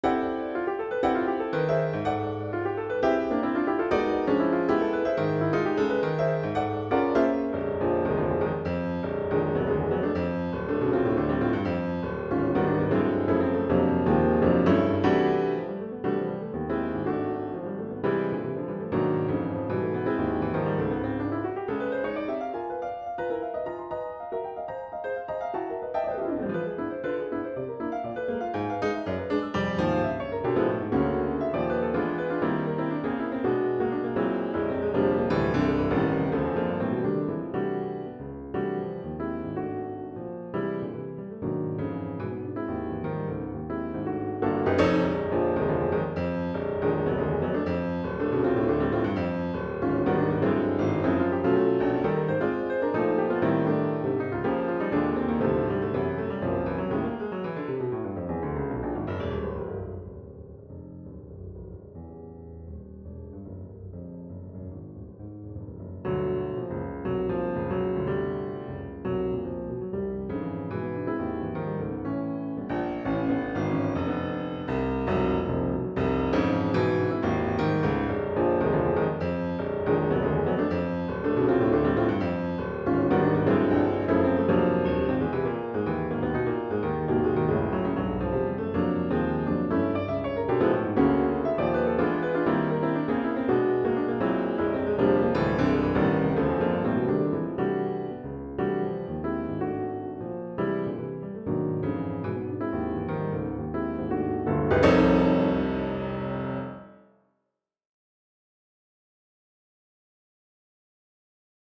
Here is a short piano piece I wrote (part of a larger "notebook" I add to when I get ideas).
PianoPieceRev1.mp3